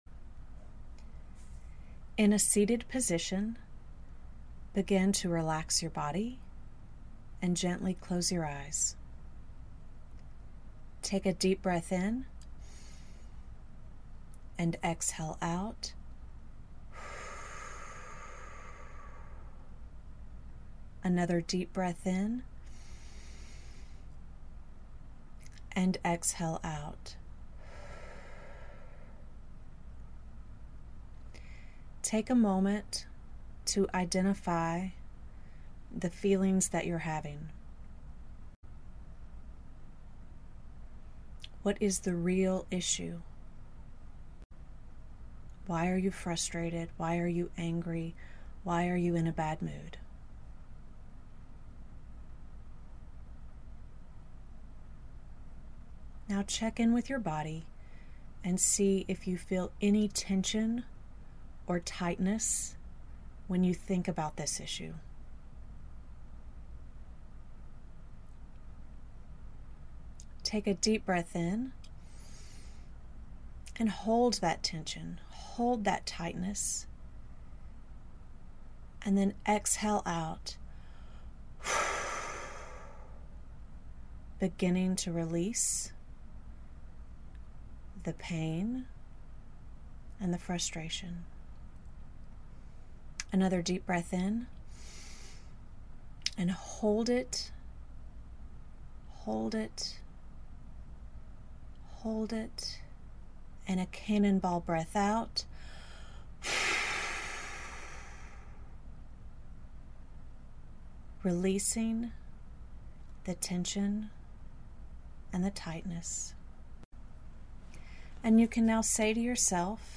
I’ve recorded a short 5-minute guided meditation for you to have handy in a pinch.